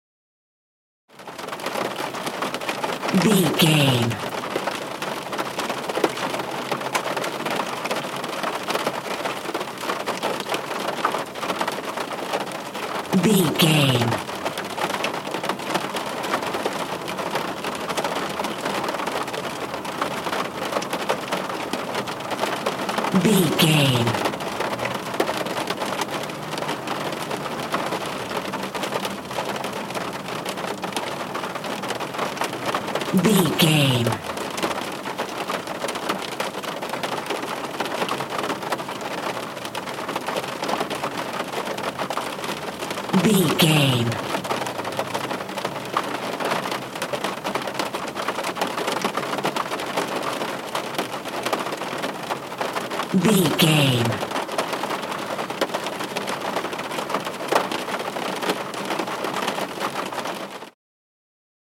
Rain int car no engine
Sound Effects
urban
ambience